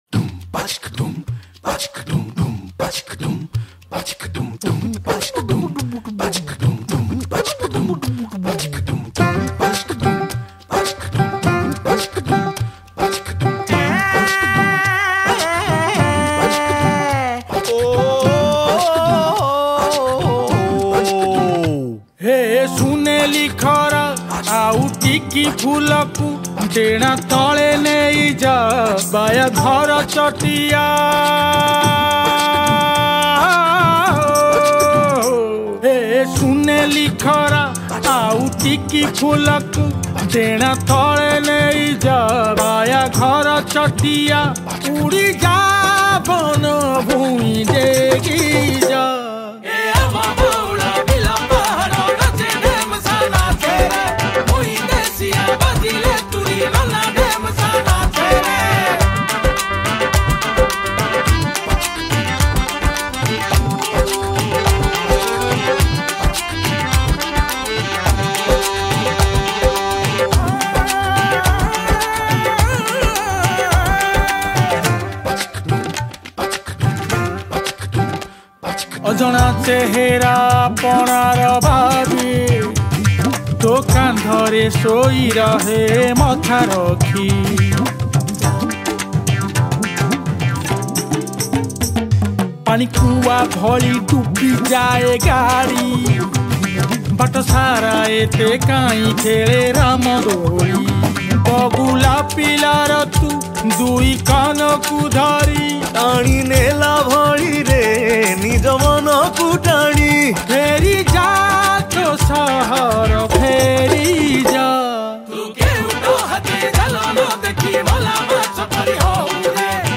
Chorus
Keyboard Programming, Rhythm Arrangements & Guitars
Dholak
Tabla